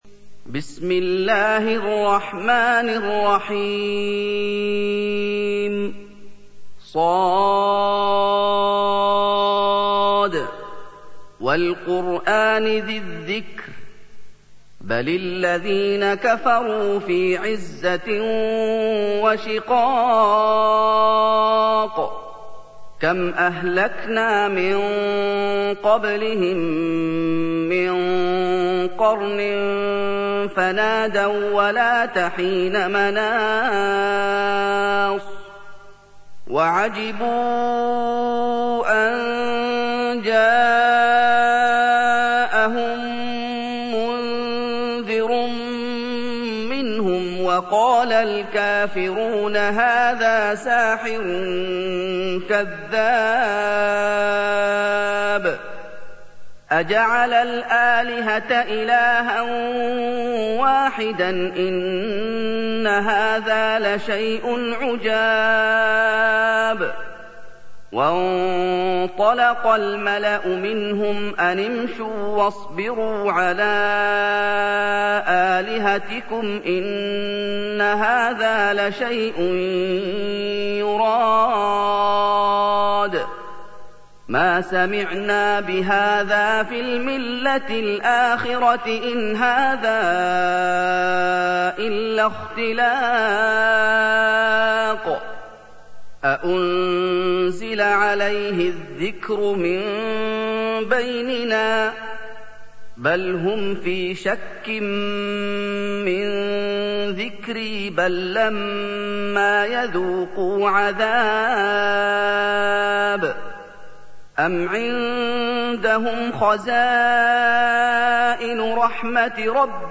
سُورَةُ ص بصوت الشيخ محمد ايوب